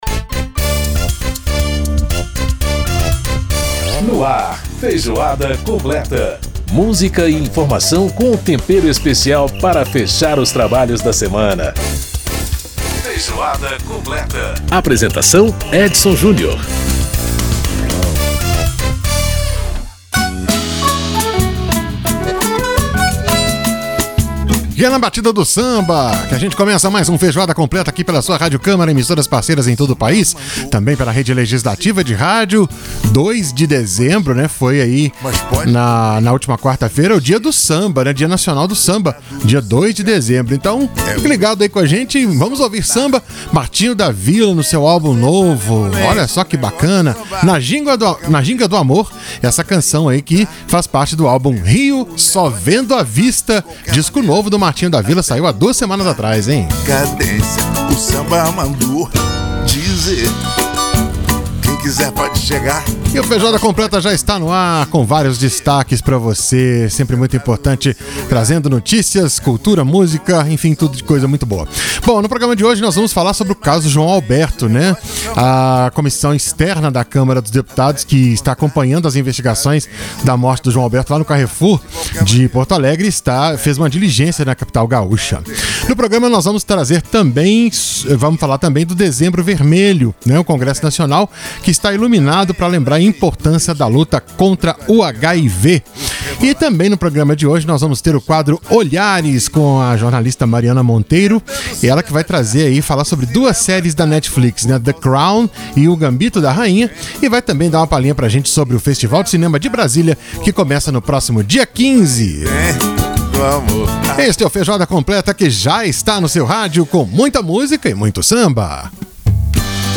Para saber detalhes da diligência, Feijoada Completa desta semana conversa com o deputado Orlando Silva (PCdoB-SP)dire integrante da comissão Externa.